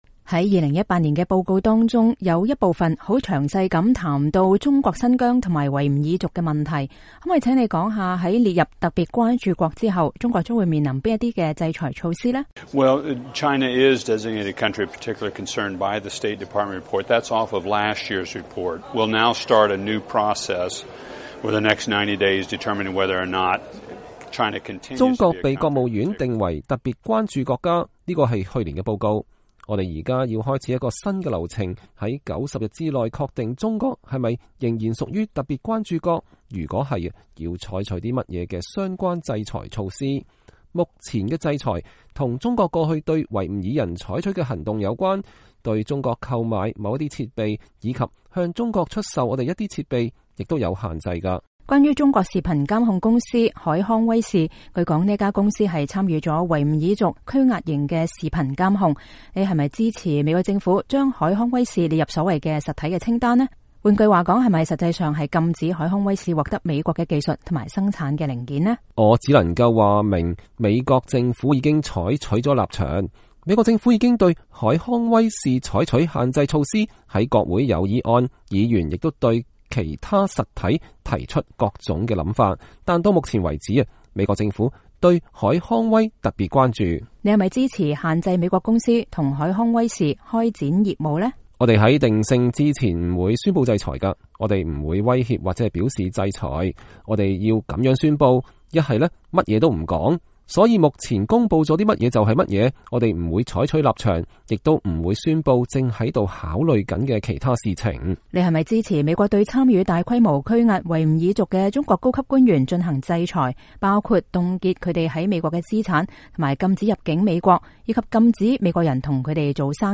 VOA專訪美宗教自由大使：中國在新疆的高壓適得其反